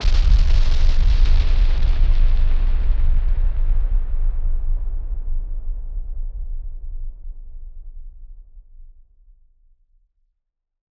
BF_SynthBomb_D-05.wav